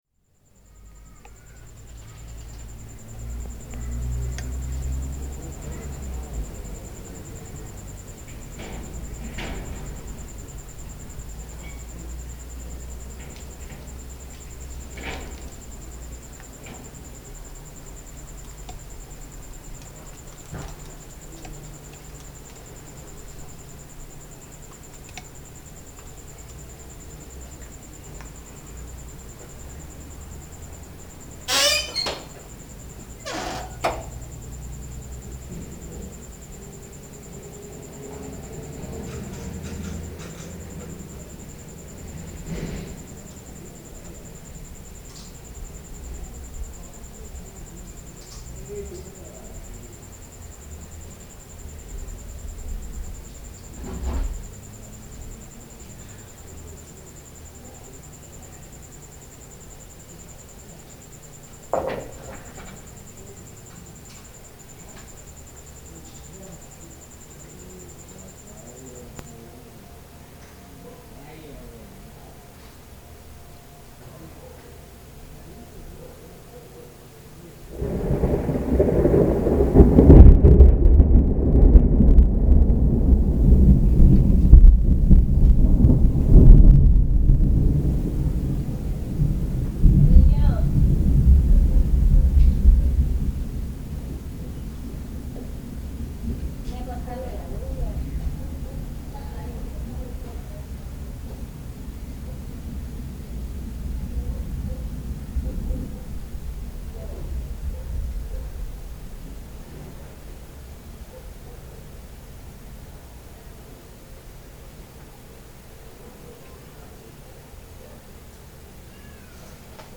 El día estuvo muy caluroso, ya es de noche pero continúa el calor, hay que abrir ventanas, afuera existe la tranquilidad. Lejos se observan algunos resplandores, luego un estruendo.
Comienza a correr el viento, el viento tiene olor a humedad, otros resplandores y estruendos más cercanos y por fin somos perdonados y nos refrescan las gotas unas por allá otras por acá, luego no distingues y al final toda la lluvia en pleno te ayuda a conciliar el sueño.
Lugar: Suchiapa, Chiapas. Mexico.
Equipo: Grabadora Sony ICD-UX80 Stereo, Micrófono de construcción casera ( más info ) Fecha: 2009-10-07 16:28:00 Regresar al índice principal | Acerca de Archivosonoro